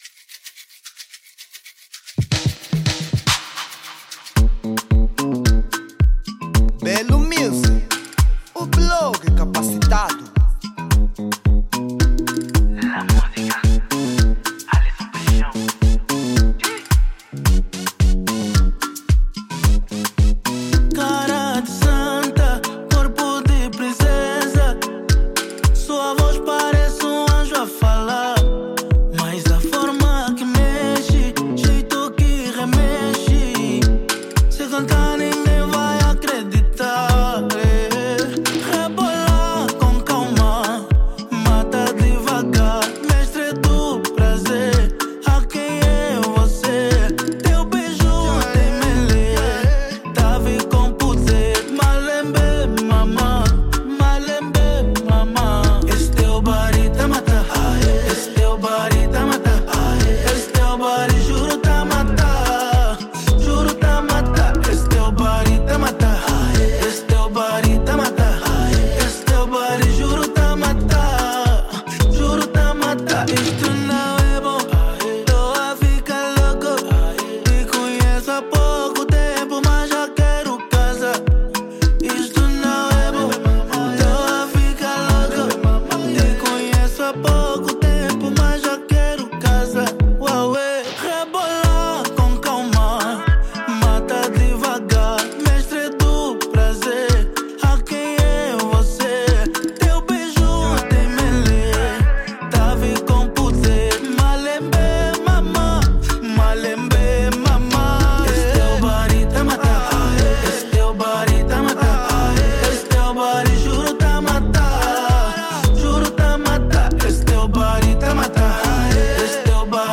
Género: Afro Beats